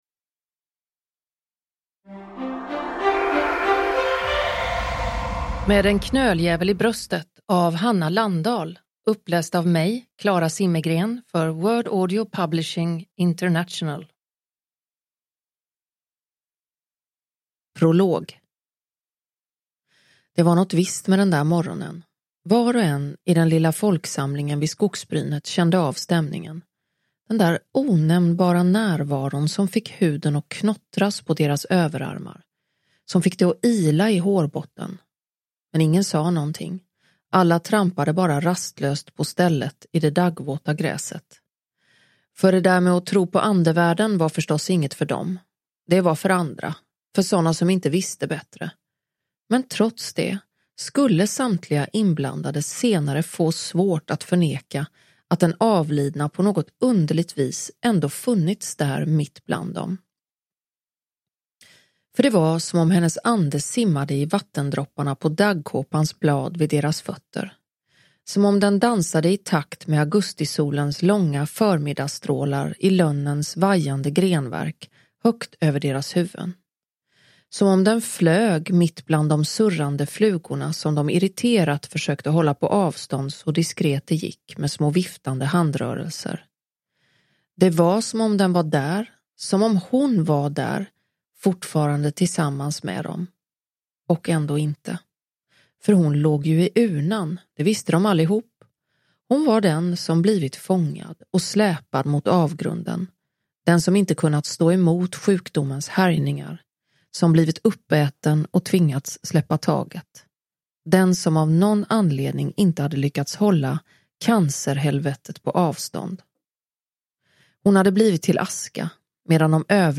Med en knöljävel i bröstet (ljudbok) av Hanna Landahl